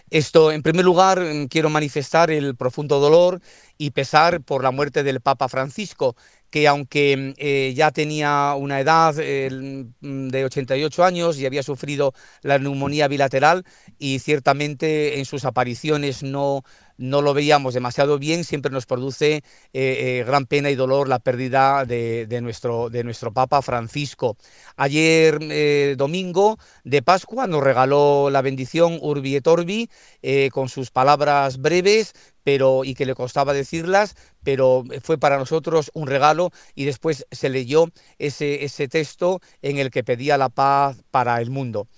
OBISPO-dolor-y-recuerdo-de-la-bendicion-de-Pascua.mp3